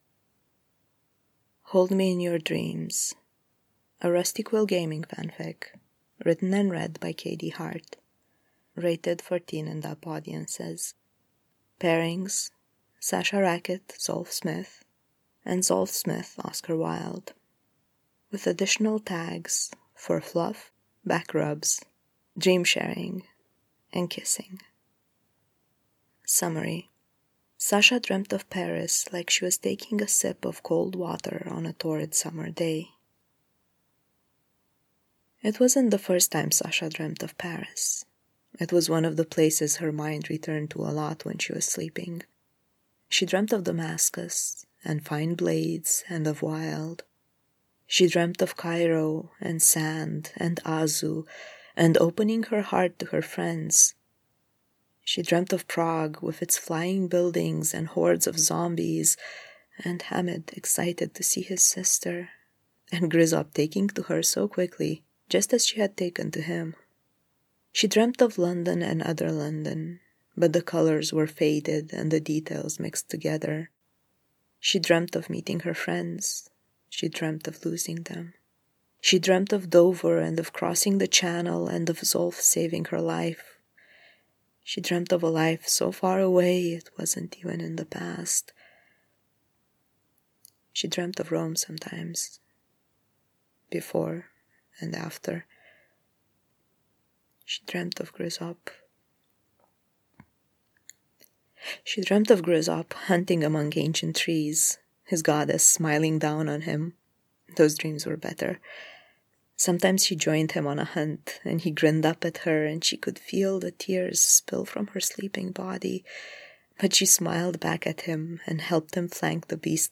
Hold Me in Your Dreams [podfic]